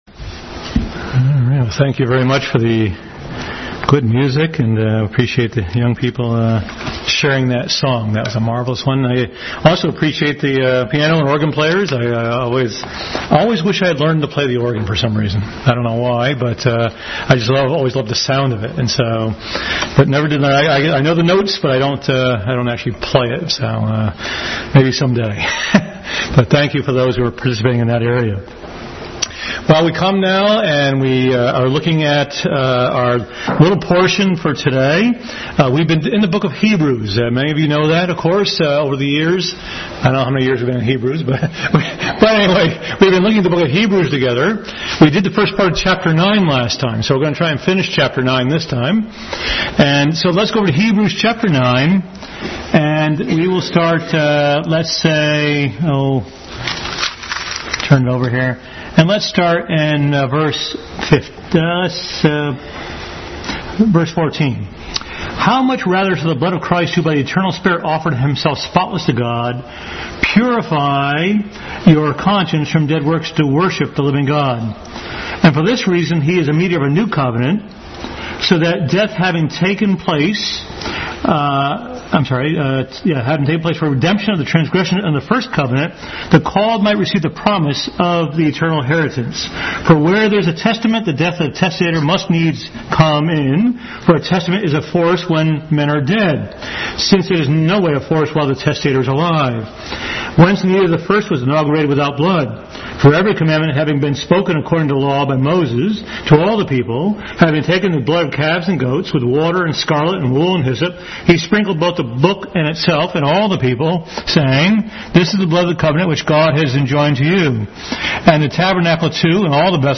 Hebrews 9:14-28 Service Type: Family Bible Hour Bible Text